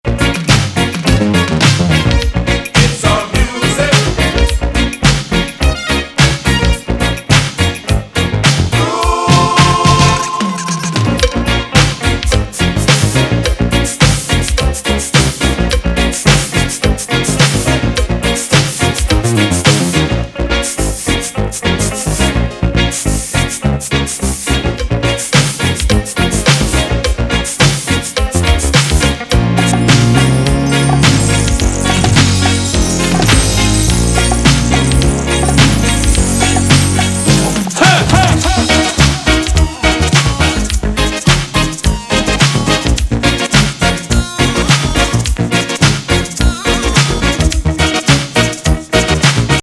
スペーシー&ダビーなレゲー・エレクトロ・ファンクB面!